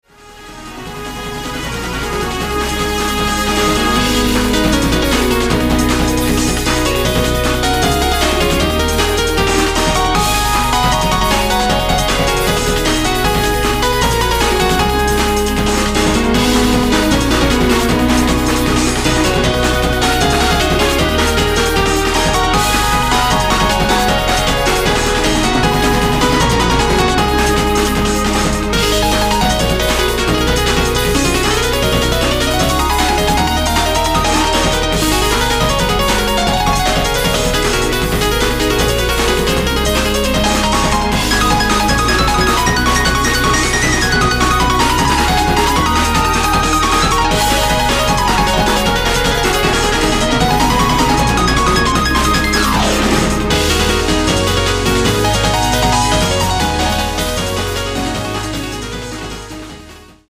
Track 1-11 ... リマスタリングver